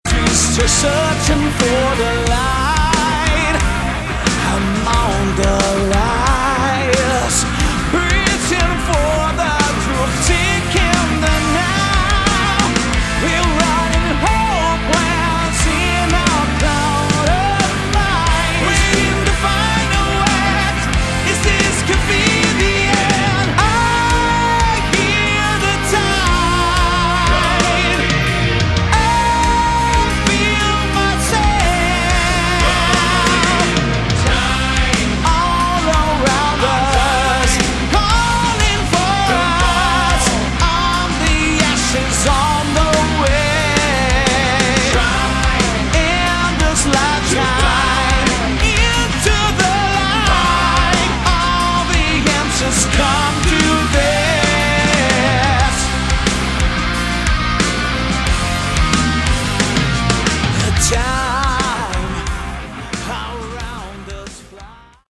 Category: Melodic Hard Rock
Vocals
Guitars
Drums
Bass
Backing vocals